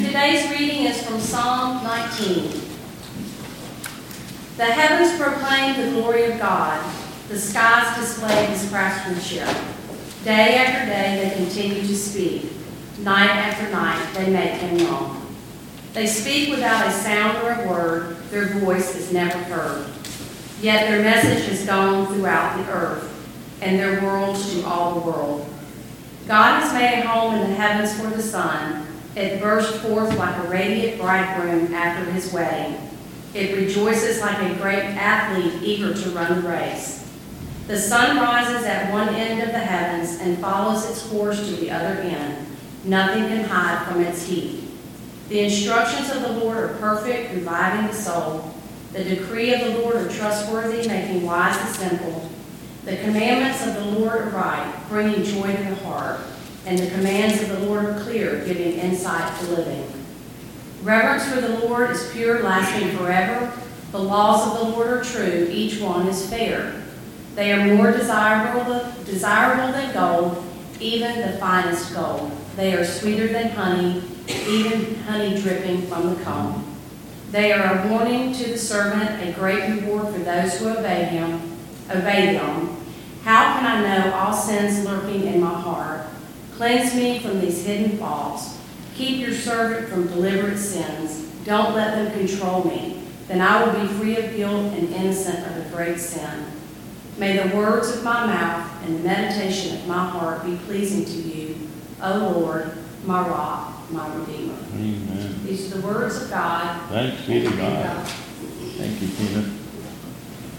2022 Bethel Covid Time Service
Old Testament Reading - Psalm 19